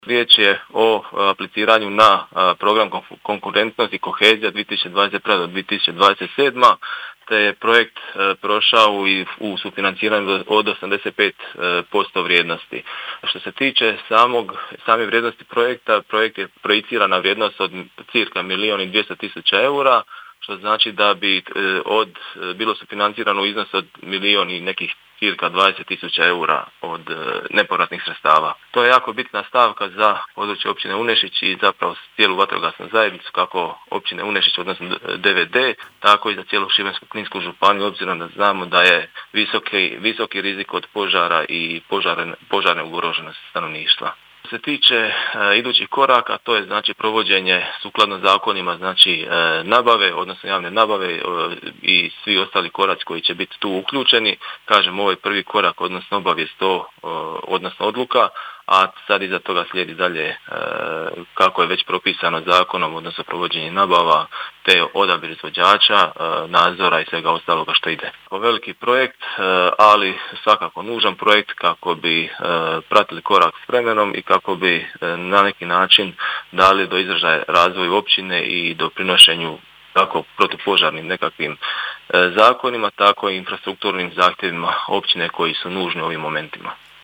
Načelnik Općine Unešić Marko Parat: